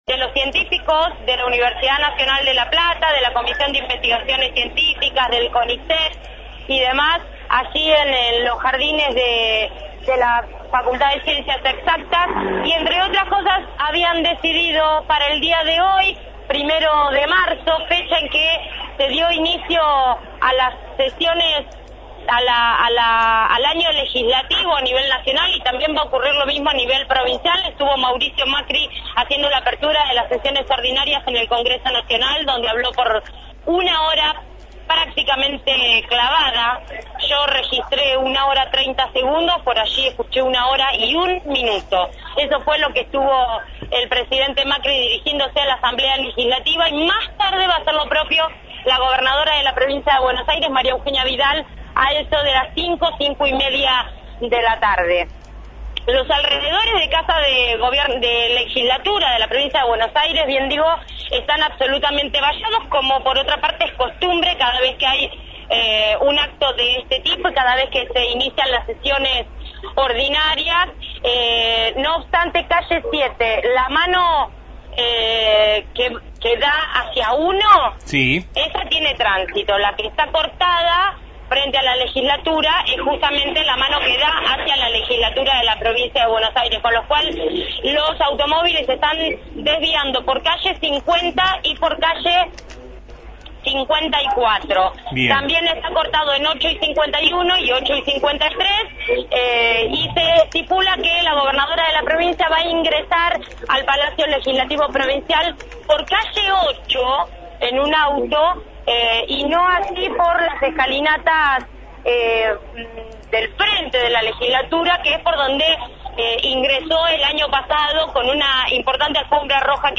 Feria de Ciencias en la Legislatura – Radio Universidad